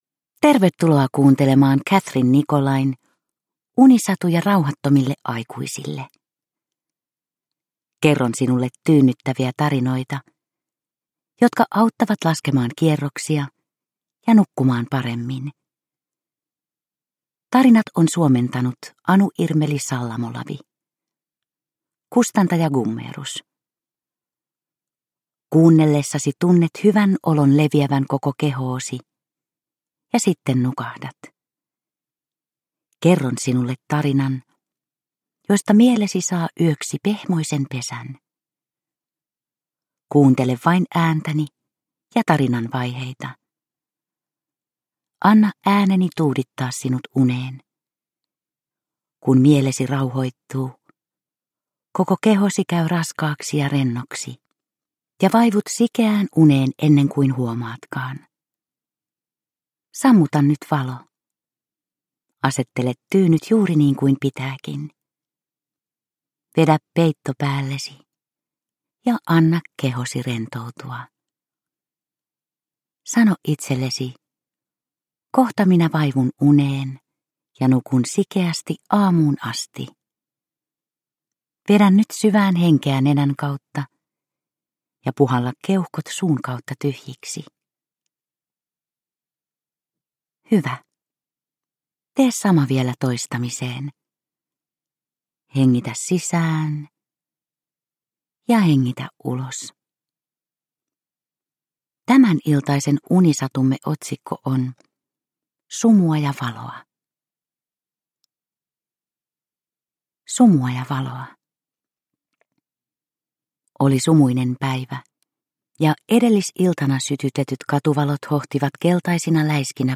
Unisatuja rauhattomille aikuisille 19 - Sumua ja valoa – Ljudbok – Laddas ner